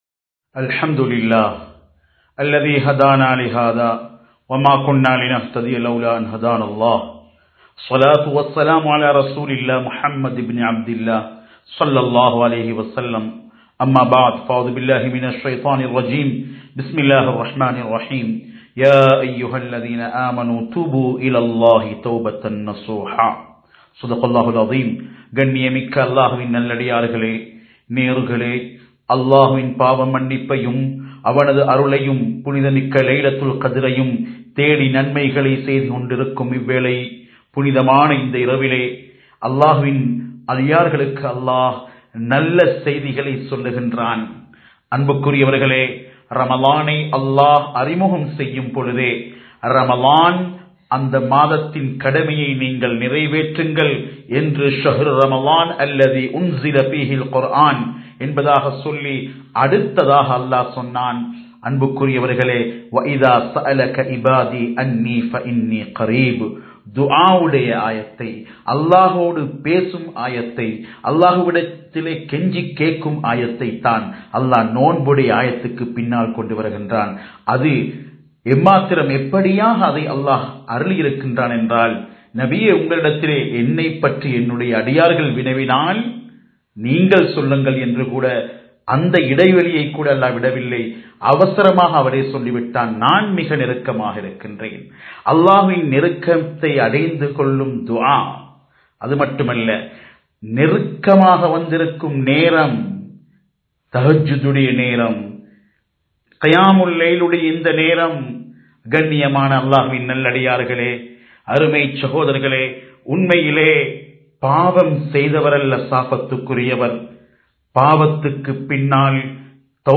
தௌபாவின் யதார்த்தம் (The Reality of Repentance) | Audio Bayans | All Ceylon Muslim Youth Community | Addalaichenai
Live Stream